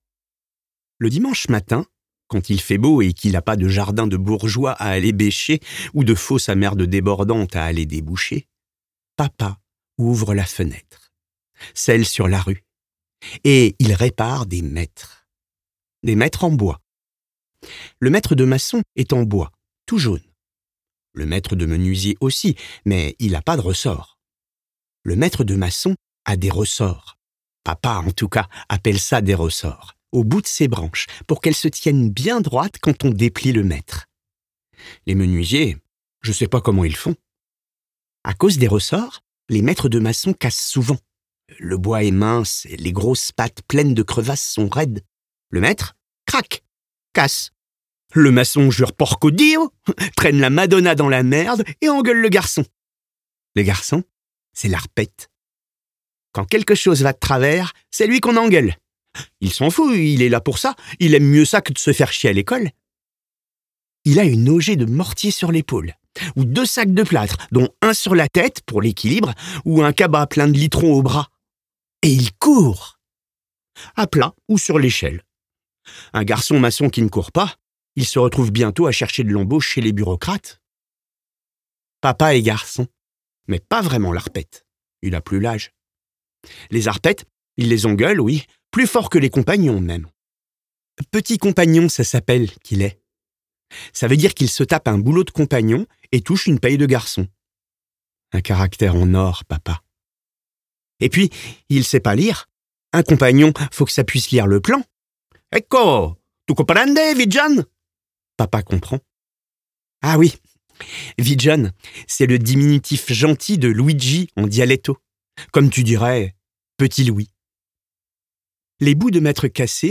LIVRE AUDIO - Les Ritals (François Cavanna)
32 - 64 ans - Baryton